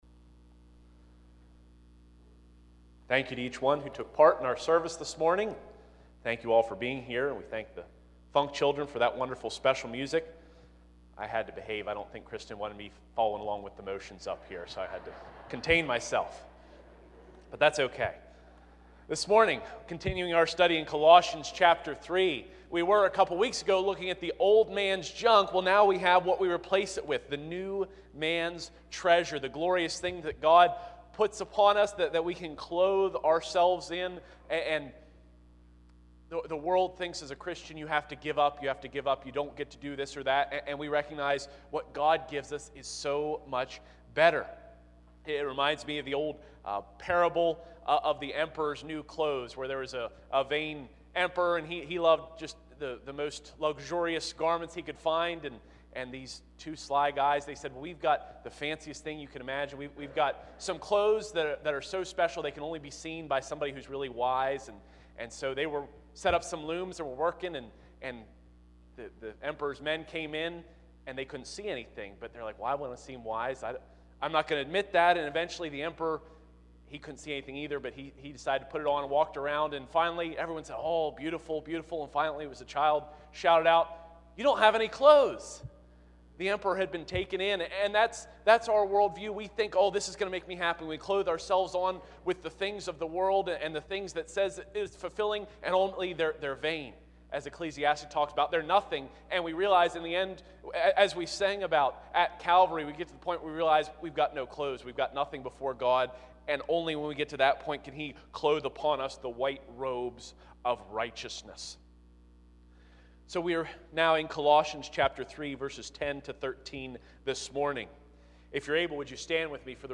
Colossians 3:10-13 Service Type: Sunday 9:30AM I. Put On Christ v. 10 II.